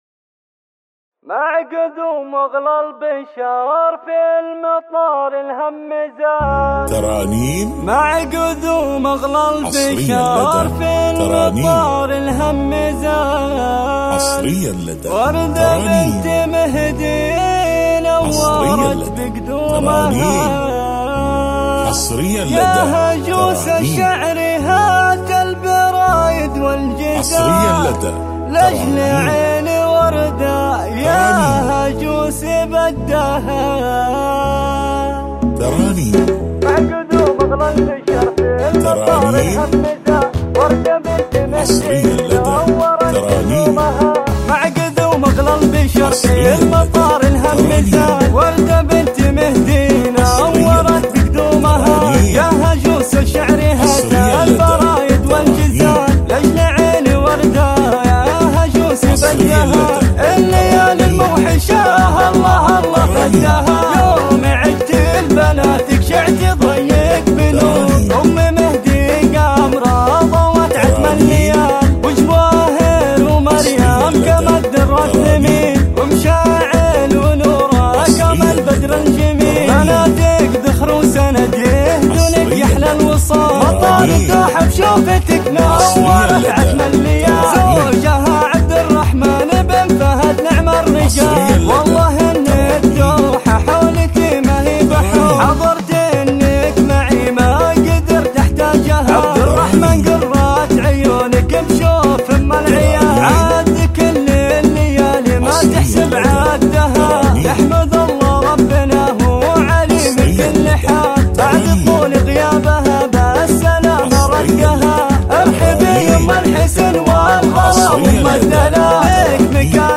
زفة شيلات
بدون موسيقى